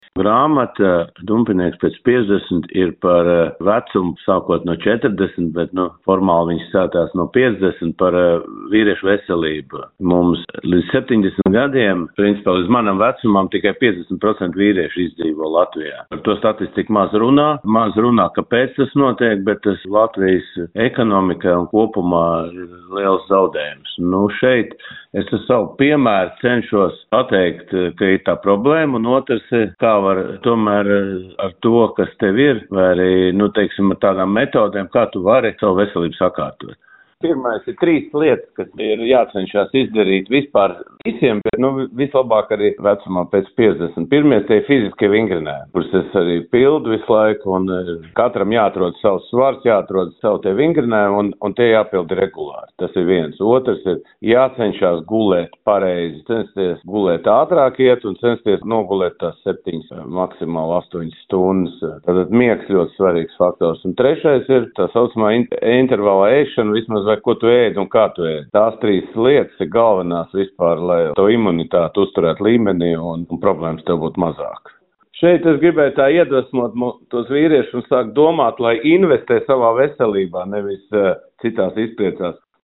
Grāmata vēsta par disciplīnas, fizisko aktivitāšu un apzinātu izvēļu nozīmi veselības saglabāšanā, to intervijā Skonto mediju grupai (SMG) atklāja grāmatas autors Valdis Valters.
grāmatas autors Valdis Valters